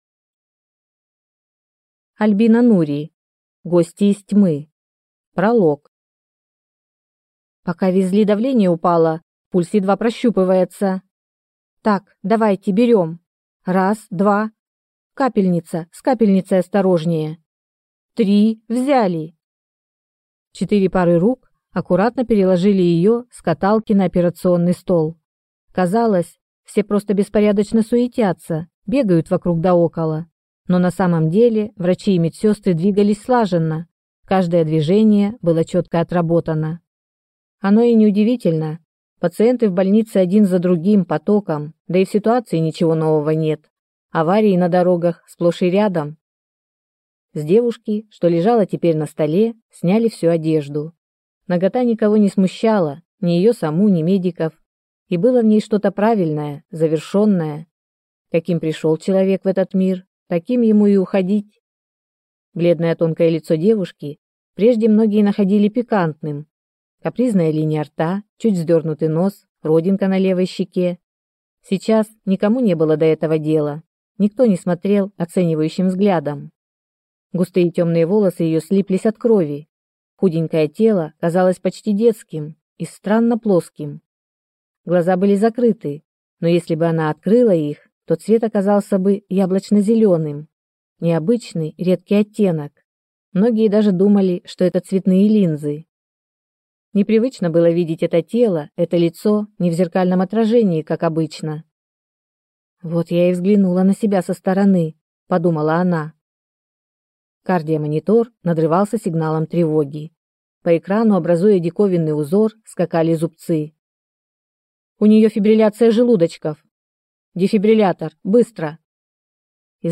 Аудиокнига Гости из тьмы | Библиотека аудиокниг